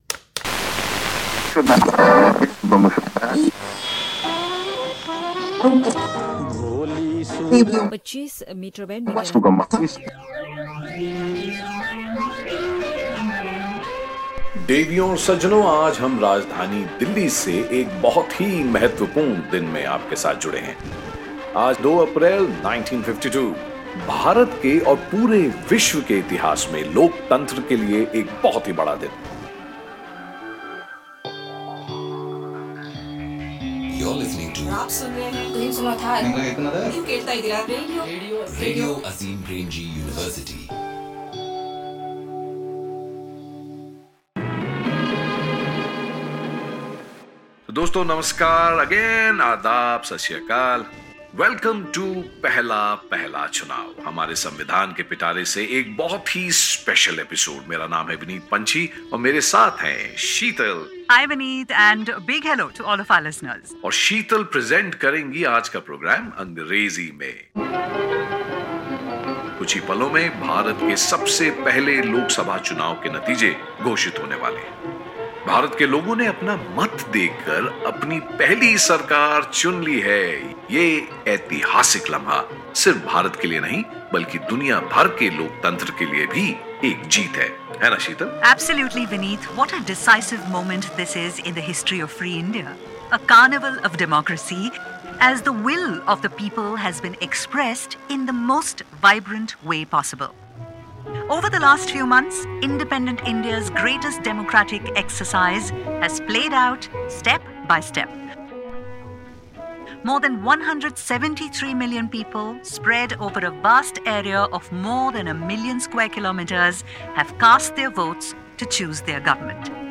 The radio crackles to life.